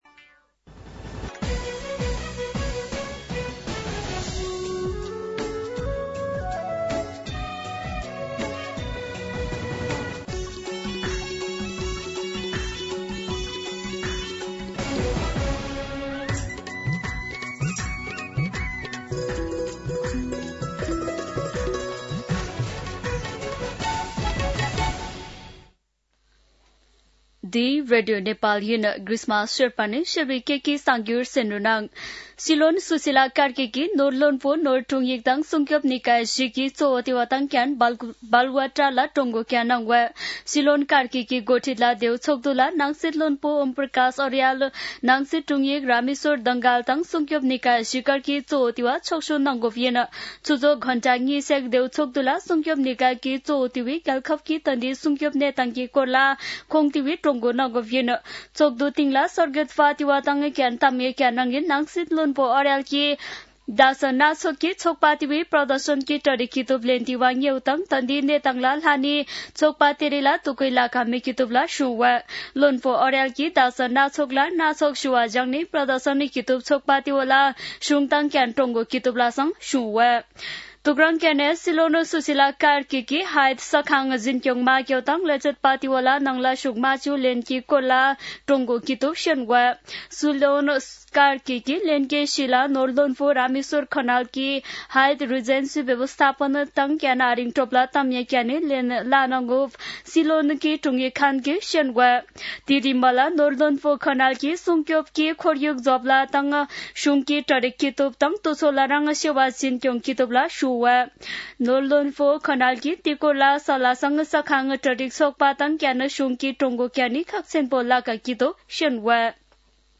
शेर्पा भाषाको समाचार : ८ कार्तिक , २०८२
Sherpa-News-07-8.mp3